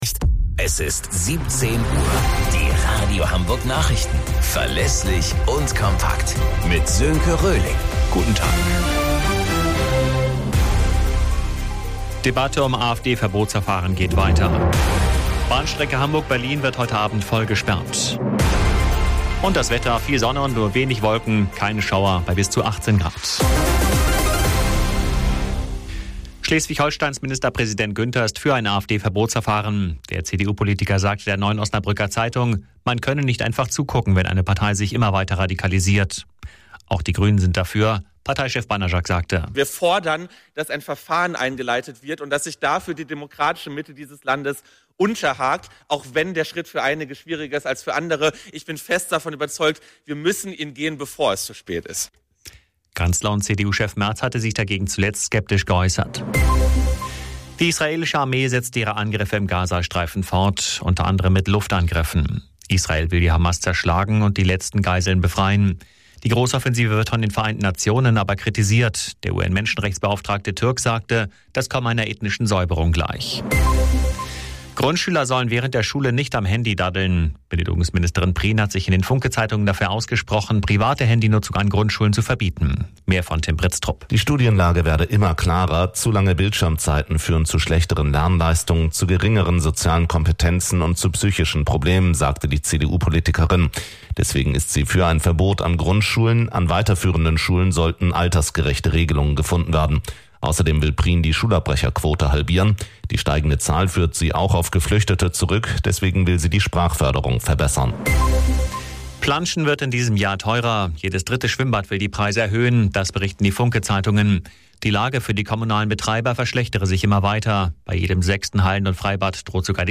Radio Hamburg Nachrichten vom 17.05.2025 um 20 Uhr - 17.05.2025